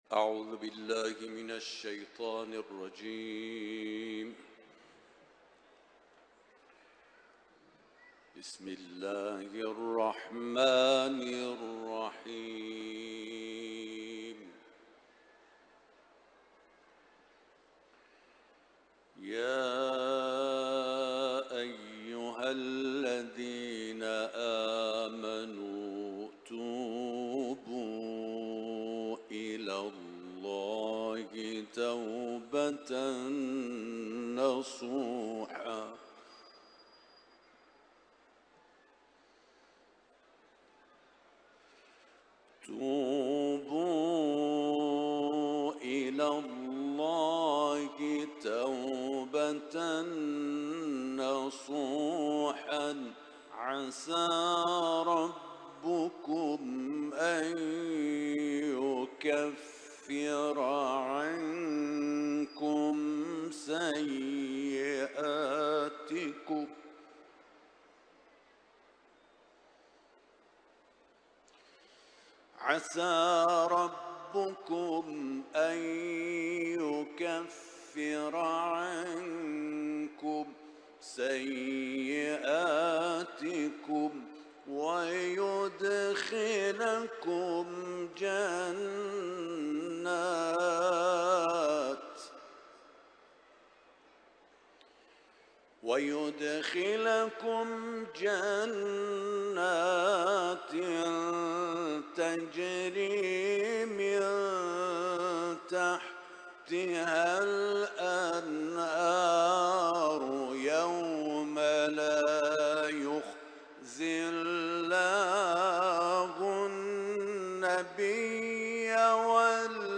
İranlı kârinin Kur’an-ı Kerim tilaveti